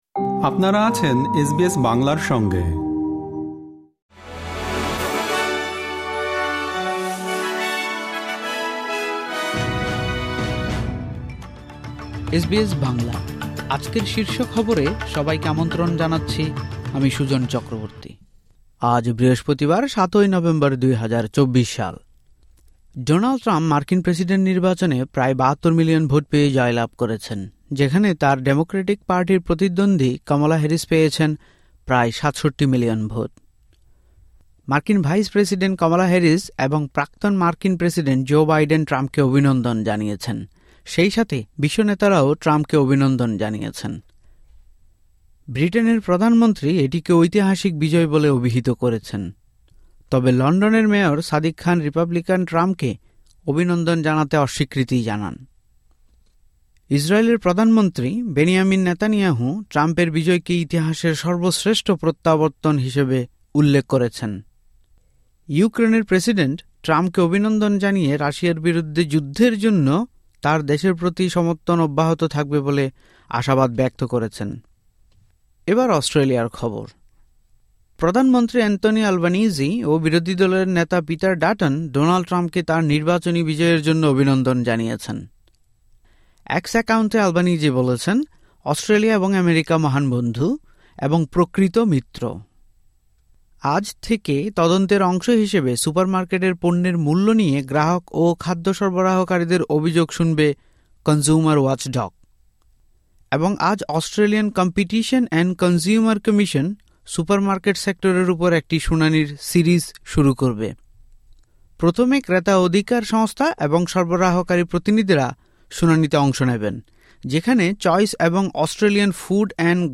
এসবিএস বাংলা শীর্ষ খবর: ৭ নভেম্বর, ২০২৪